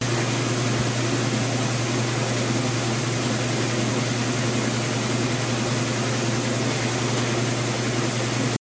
washing4.ogg